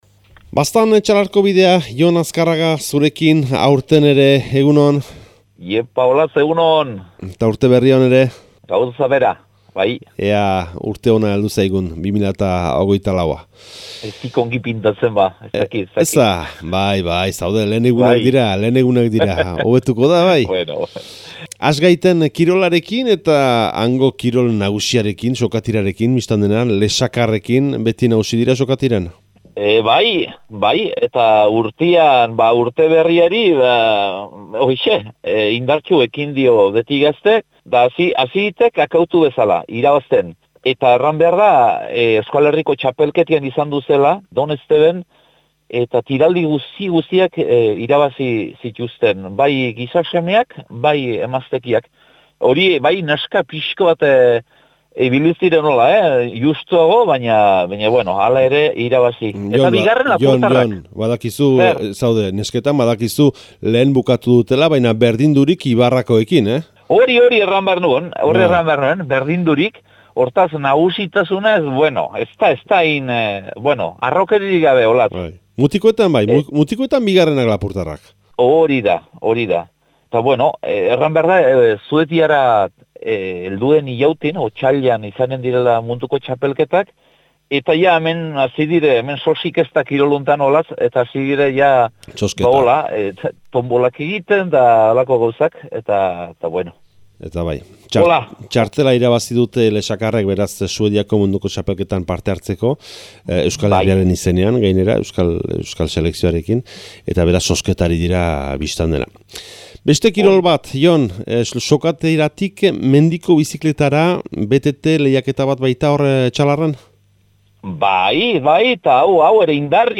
Urtarrilaren 11ko Etxalar eta Baztango berriak